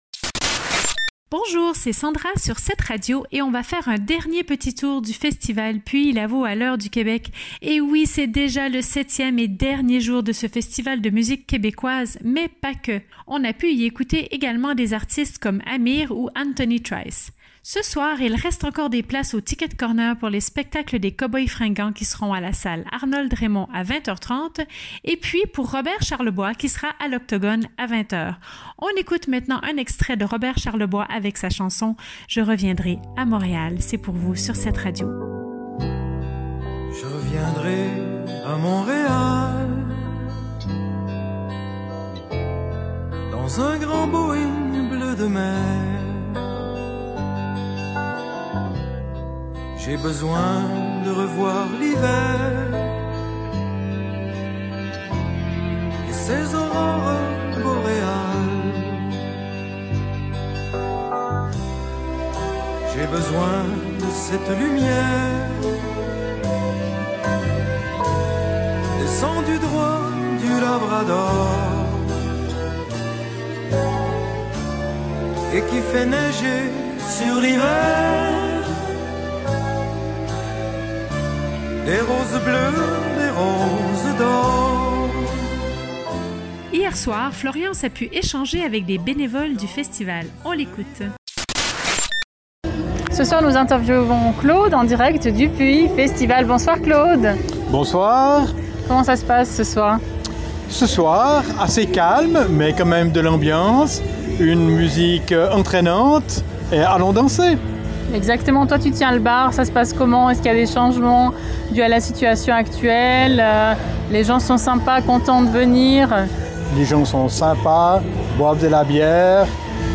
discute avec vous à la fin d’un concert